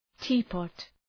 Προφορά
{‘ti:pɒt}